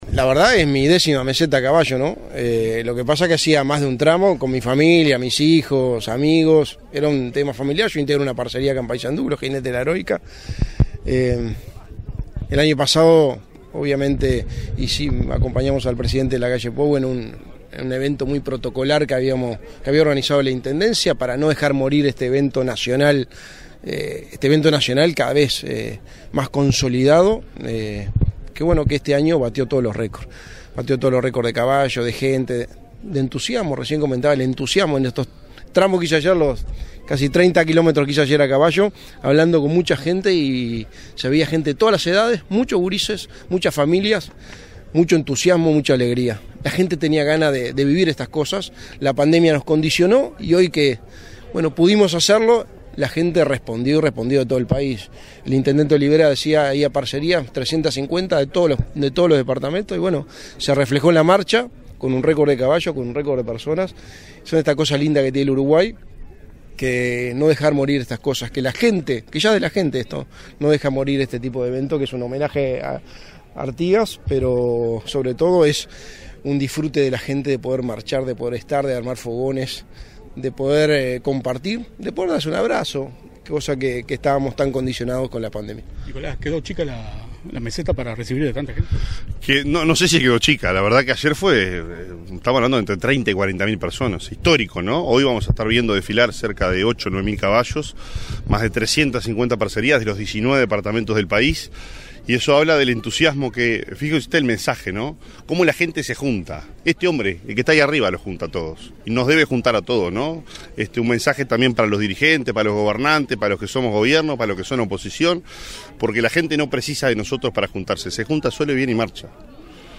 Declaraciones a la prensa del secretario de la Presidencia, Álvaro Delgado, y el intendente de Paysandú, Nicolás Olivera
Declaraciones a la prensa del secretario de la Presidencia, Álvaro Delgado, y el intendente de Paysandú, Nicolás Olivera 25/09/2022 Compartir Facebook X Copiar enlace WhatsApp LinkedIn Este 25 de setiembre, se realizó el 28.° Encuentro con el Patriarca, en la Meseta de Artigas, en Paysandú. Tras el evento, el secretario de Presidencia, Álvaro Delgado, y el intendente de Paysandú, Nicolás Olivera, realizaron declaraciones a la prensa.